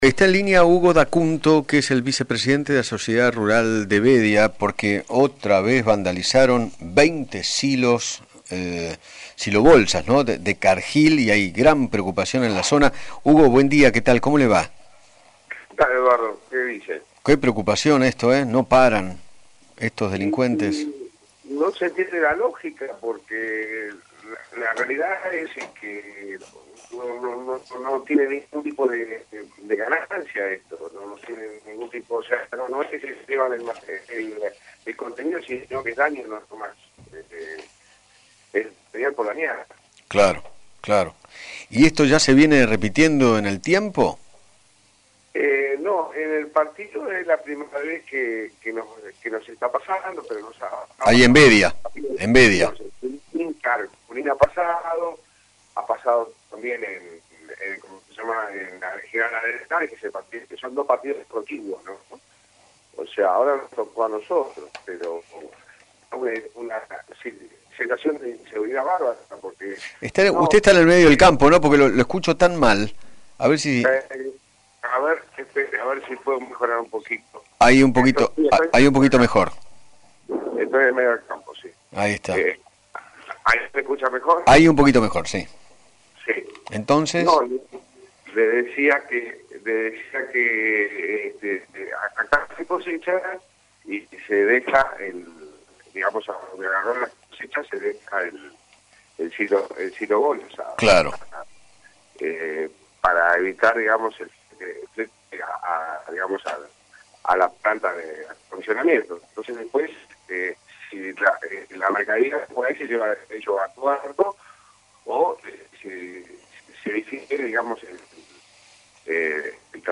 dialogó con Eduardo Feinmann sobre el vandalismo que sufrió la cerelera Cargill